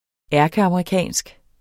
Udtale [ ˈæɐ̯gəɑmɑiˈkaˀnsg ]